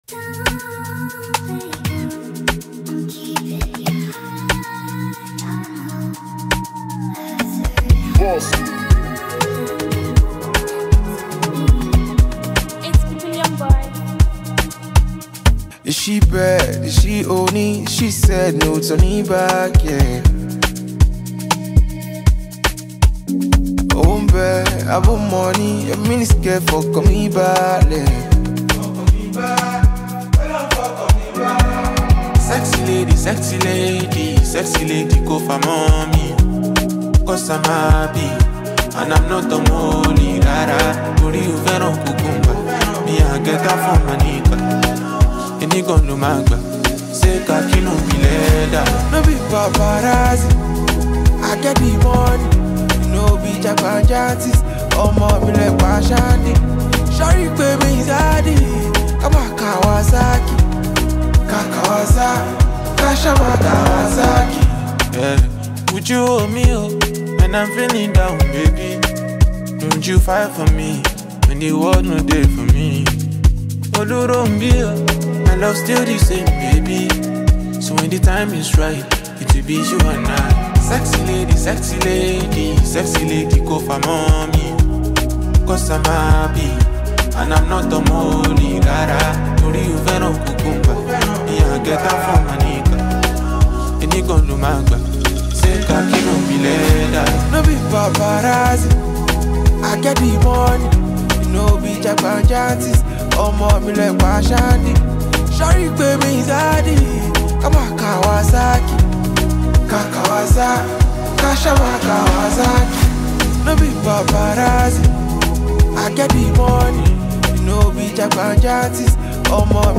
catchy Afrobeats single